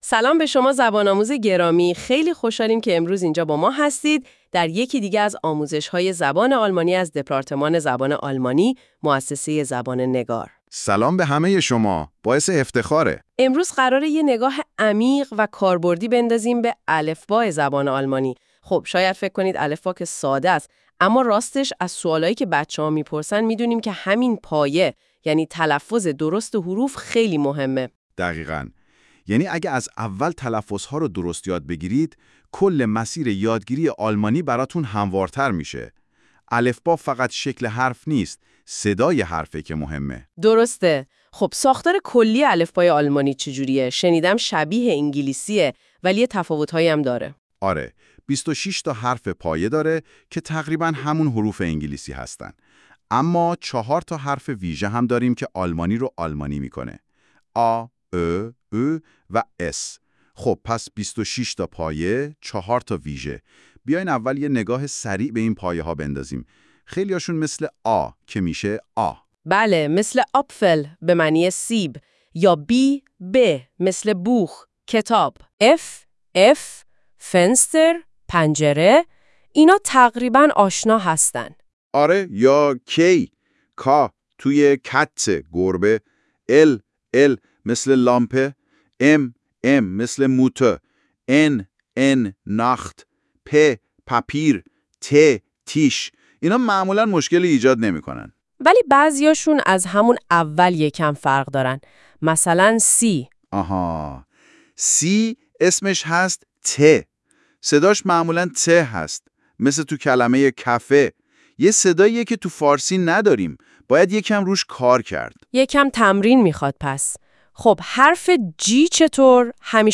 آموزش-الفبای-آلمانی-و-تلفظ-حروف.wav